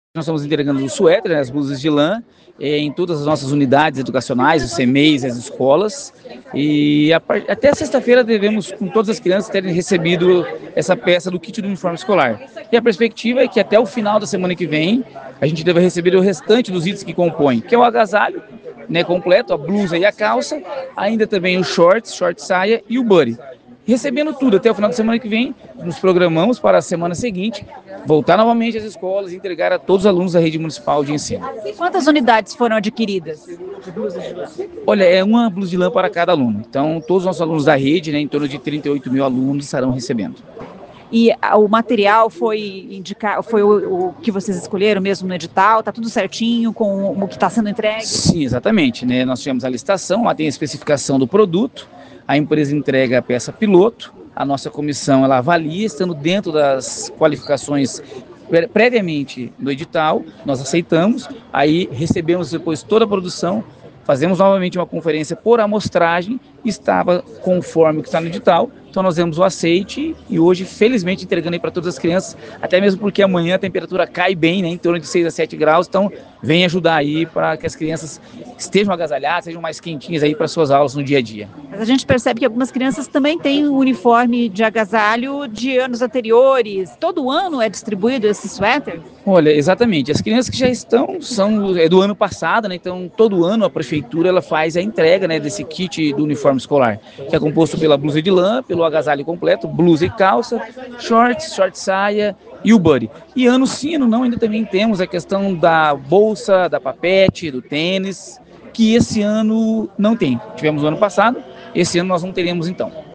Ouça o que diz o secretário de Educação, Fernando Brambilla: